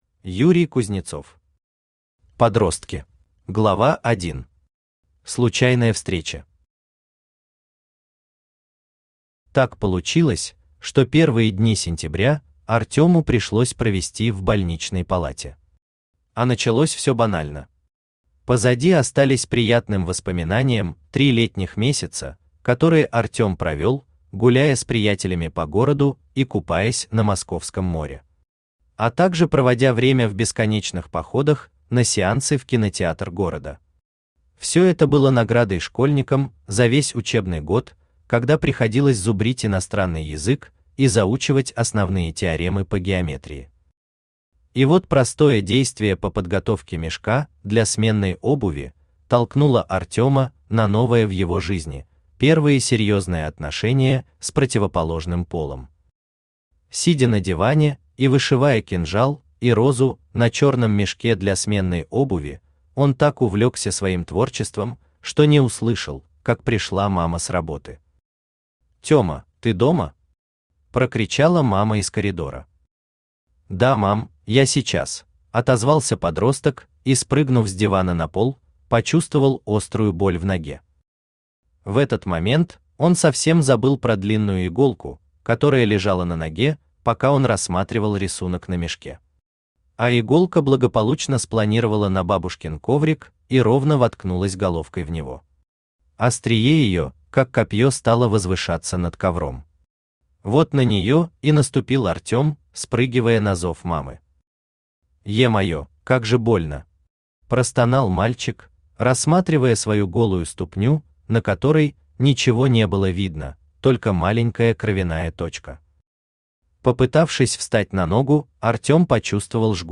Aудиокнига Подростки Автор Юрий Юрьевич Кузнецов Читает аудиокнигу Авточтец ЛитРес. Прослушать и бесплатно скачать фрагмент аудиокниги